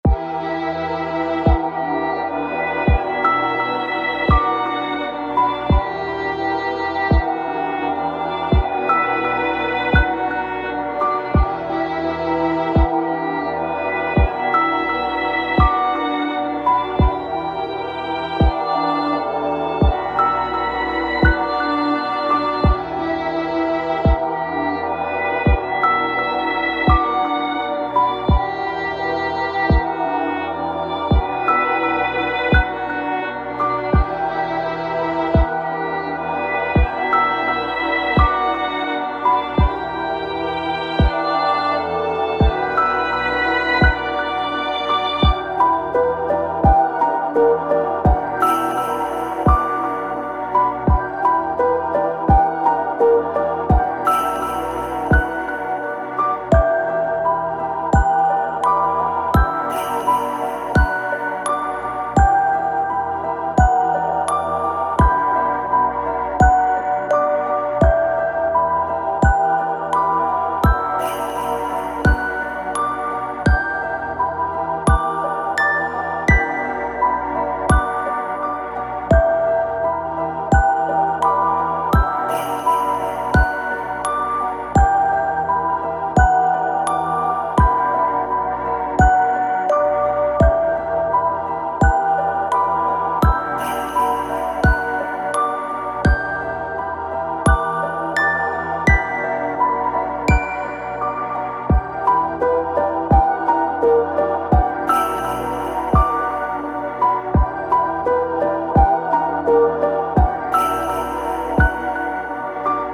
💬誰からも忘れられてしまったもの…そんな悲しいイメージを曲にしました。
少し不気味さもあるかもしれません。